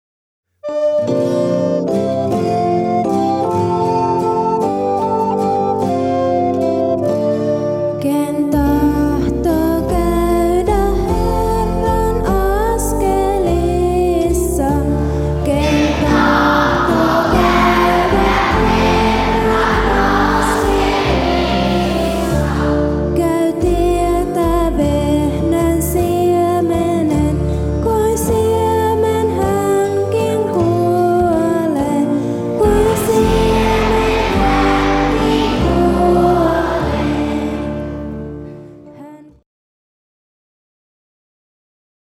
Kansanlaulupohjainen virsi 61 on aivan poikkeuksellinen. Sen luontevin laulutapa on esilaulajan ja seurakunnan jatkuva vuorottelu niin, että seurakunta toistaa esilaulajan laulamat samansisältöiset säkeet.